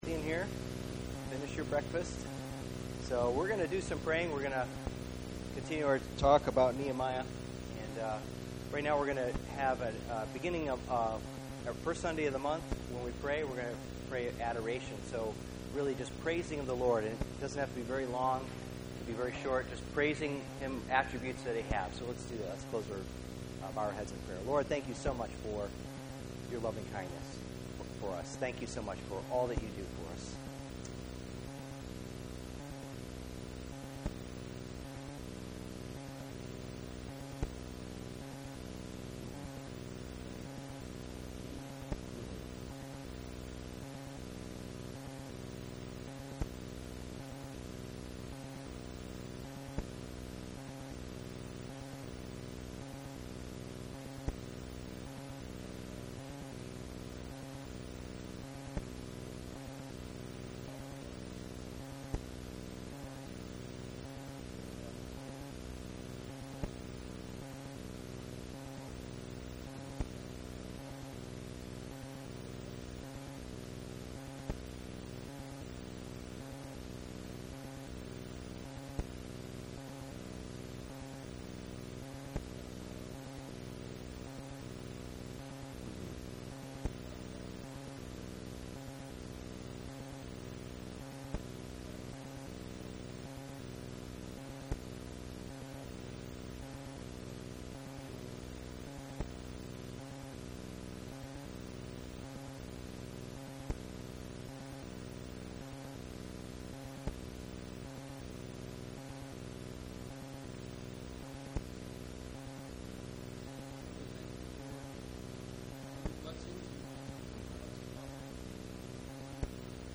Book of Nehemiah Service Type: Sunday Morning %todo_render% « Nehemiah 5